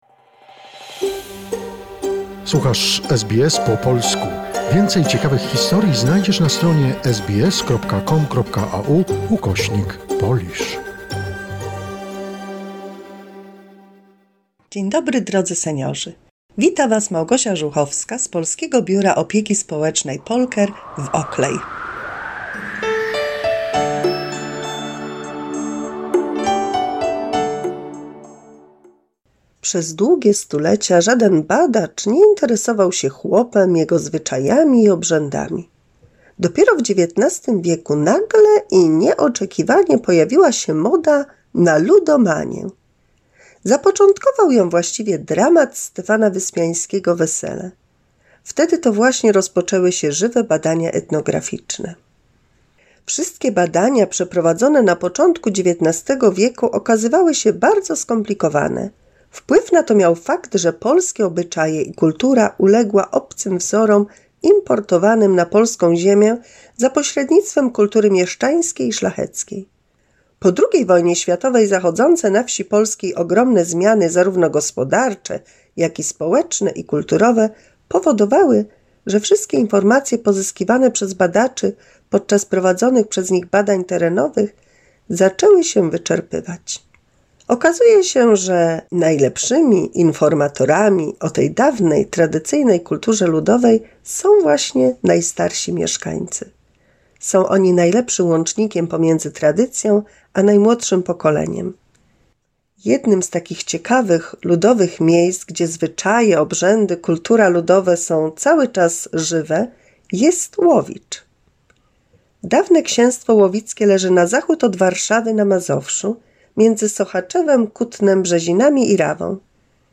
103 mini słuchowisko dla polskich seniorów